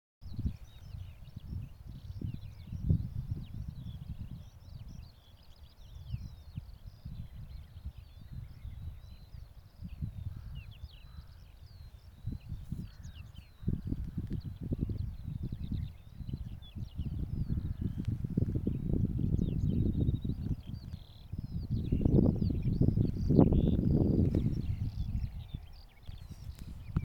луговой чекан, Saxicola rubetra
СтатусПоёт